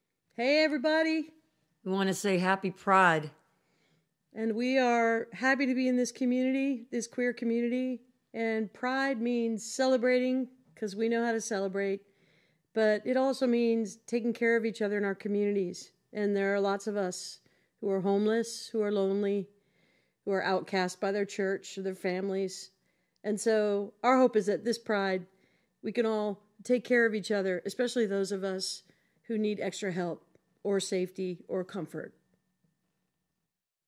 (captured from the youtube broadcast)
02. talking with the crowd (indigo girls) (0:33)